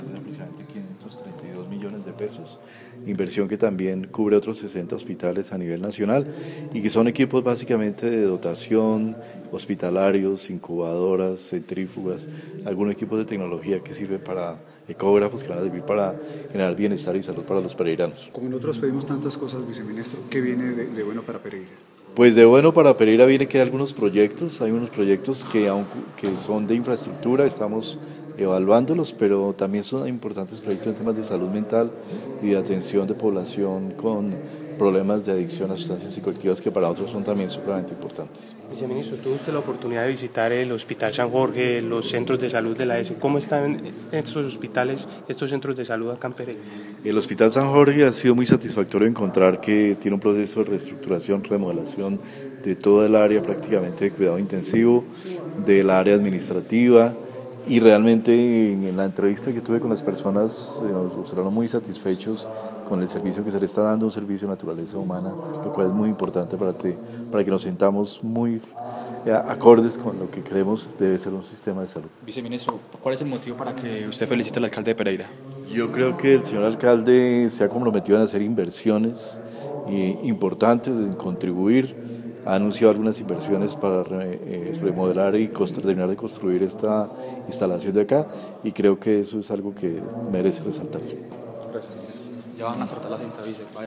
Audio: declaraciones viceministro Fernando Ruiz Gómez en ESE Salud Pereira